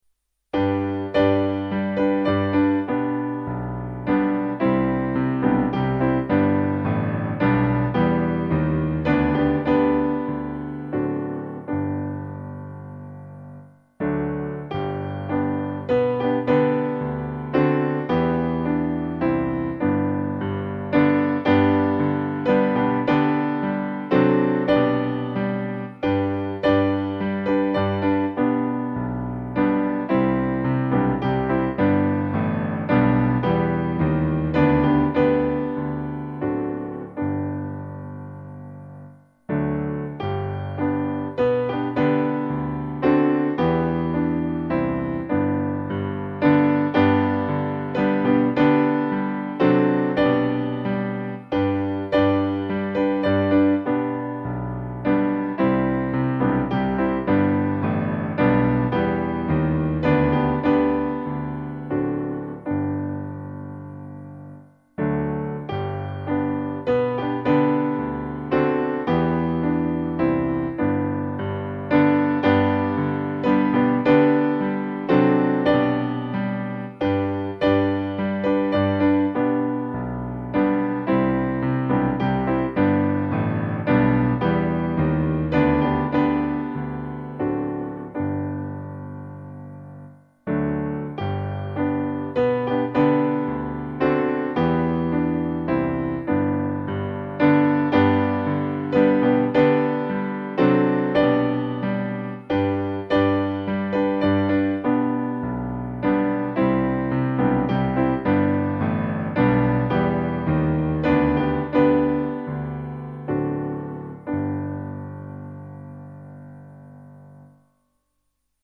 Amazing Grace Piano (.mp3)
Music by: 19th cent. USA melody;
AmazingGracePiano.mp3